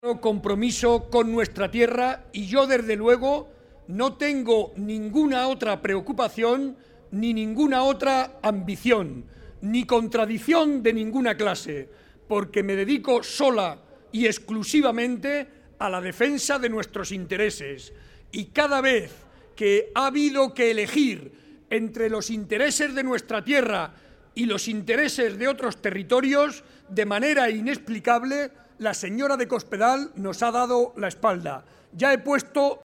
Audio Barreda mitin Guadalajara 3